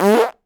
pgs/Assets/Audio/Comedy_Cartoon/fart_squirt_10.WAV at master
fart_squirt_10.WAV